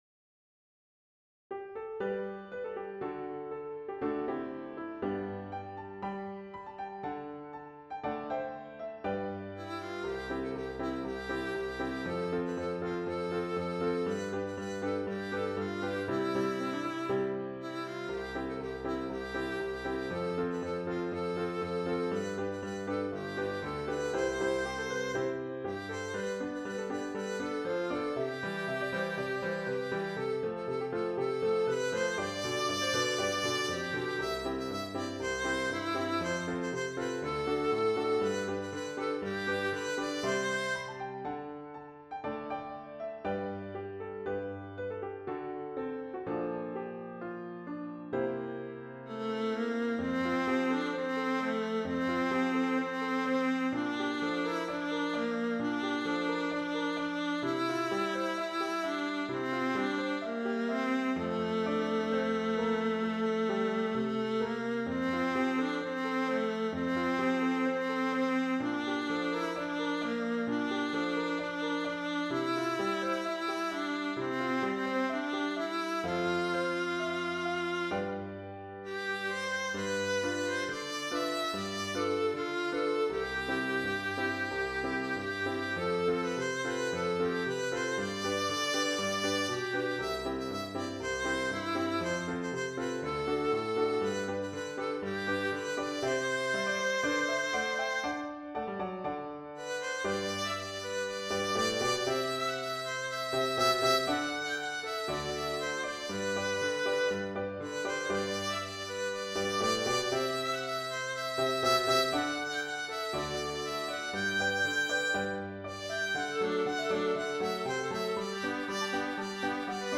Intermediate Instrumental Solo with Piano Accompaniment.
Christian, Gospel, Sacred.
set to a fast past, energetic jig.